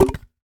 Sfx Nerf Gun Impact Sound Effect
sfx-nerf-gun-impact-1.mp3